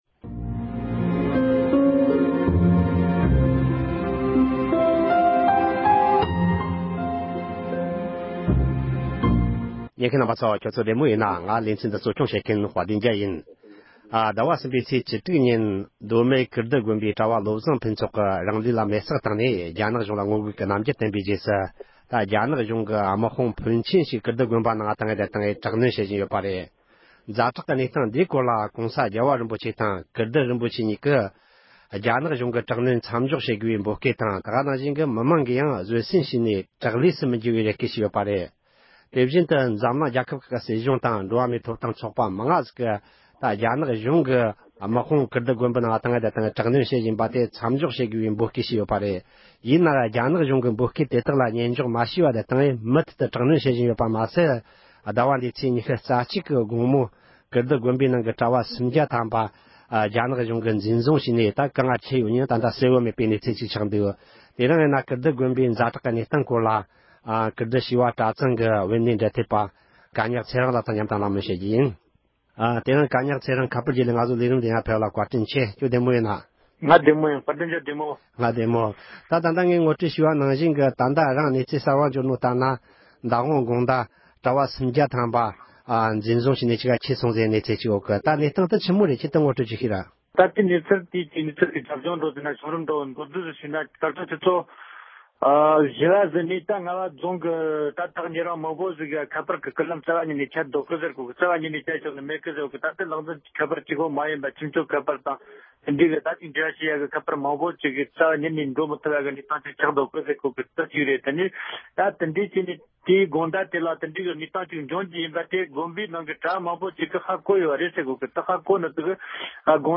གླེང་མོལ་བྱས་པར་ཉན་རོགས་གནོངས།